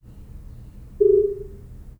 tones400hz25hzam
Binaural recordings of 400 Hz tones with 25 Hz sinusoidal amplitude modulations (0-100% depth) played from a loudspeaker in my dining room.
Binaural recordings for 0° azimuth, cropped 1 second before and after the recorded tone’s onset.
channels reversed